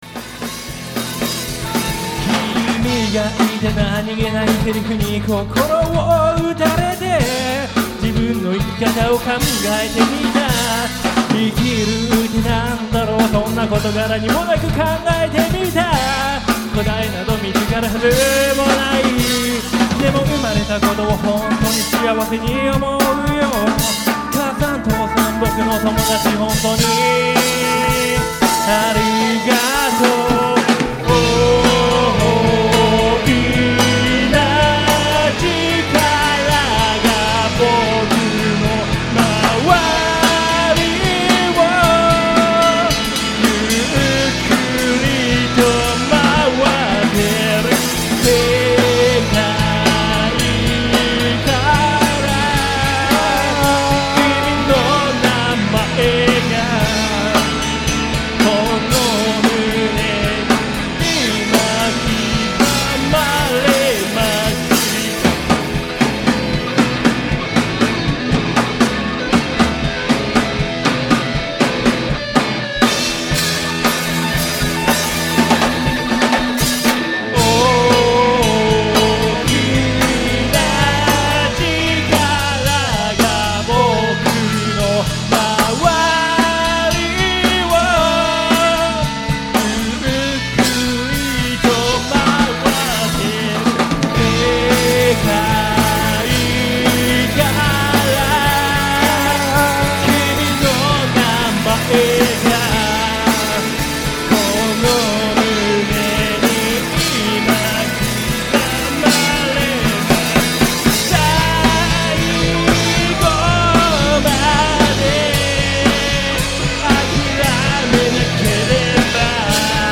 国立リバプールでのLIVE音源
Guitar＆Vocal
Lead Guitar
Bass
Drums＆Chorus